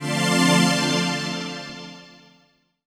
DM PAD6-1.wav